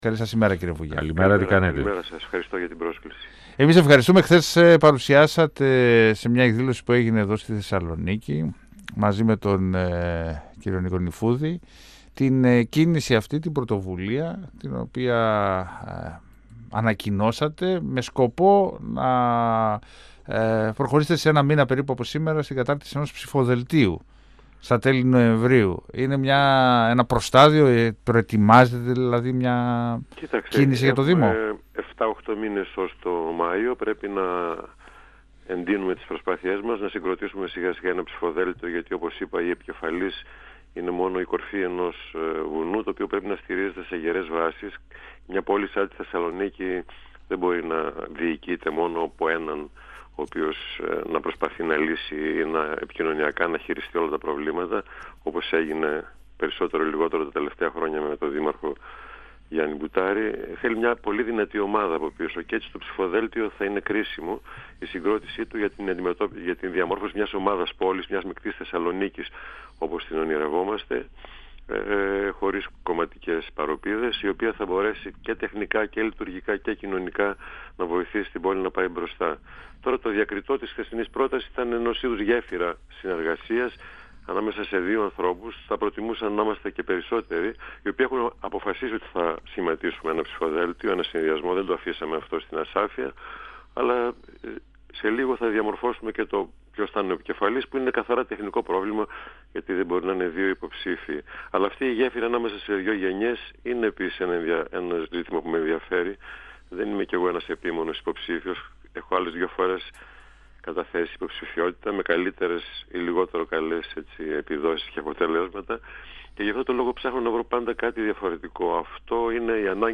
Συνέχεια αλλά και τομή της σημερινής διοίκησης του Δήμου επιθυμεί να αποτελέσει το σχήμα που αποτελείται από τους Σπύρο Βούγια και Νίκου Νυφούδη που αναμένεται να διεκδικήσει την διοίκηση του Δήμου Θεσσαλονίκης. Ο κ. Βούγιας αναφέρθηκε στις επόμενες κινήσεις του συνδυασμού, μιλώντας στον 102fm του Ραδιοφωνικού Σταθμού Μακεδονίας της ΕΡΤ3, ενώ πρόσθεσε ότι το θέμα του υποψηφίου για τη δημαρχία της Θεσσαλονίκης θα ξεκαθαρίσει στο επόμενο διάστημα.
Συνεντεύξεις